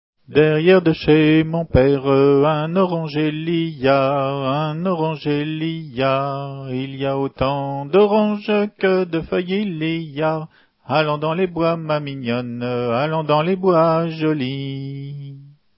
Entendu au fest-noz de Monterfil en juin 88